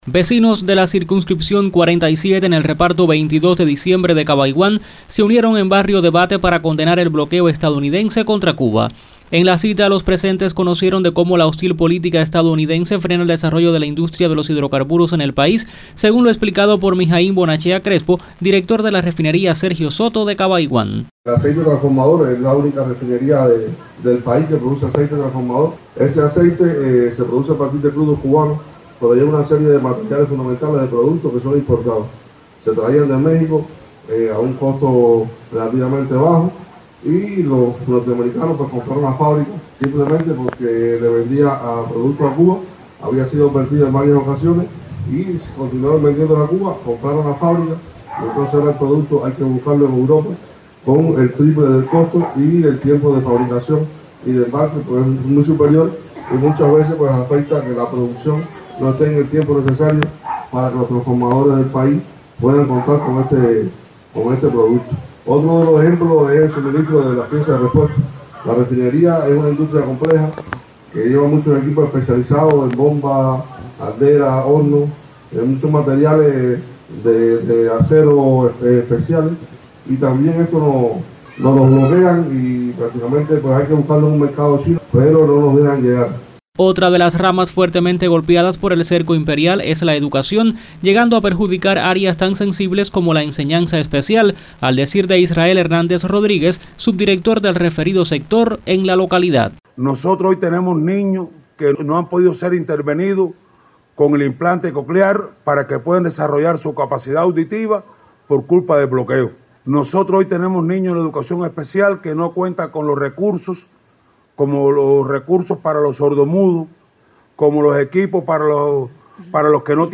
Vecinos de la circunscripción 47 en el Reparto 22 de Diciembre de Cabaiguán se unieron en barrio debate para condenar el bloqueo estadounidense contra Cuba.
Barrio-debate-bloqueo-1.mp3